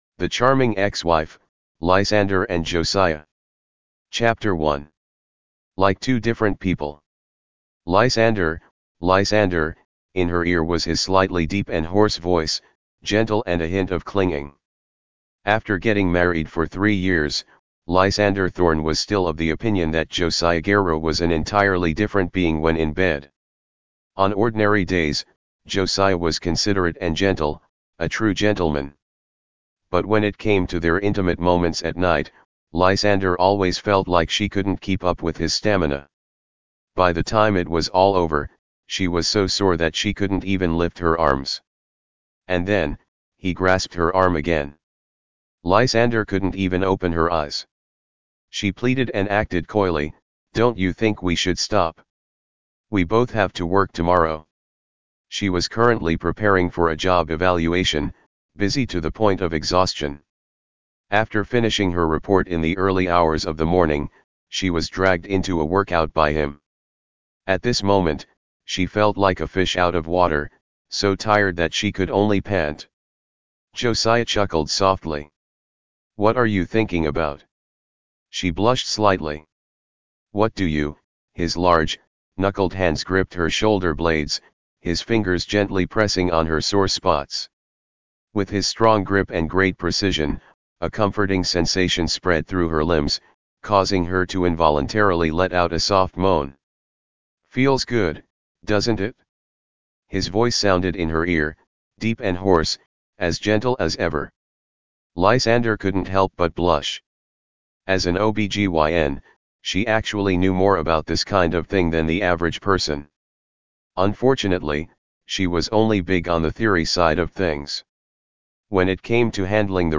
The Charming Ex-Wife Audiobook and PDF version Chapter 1 to 10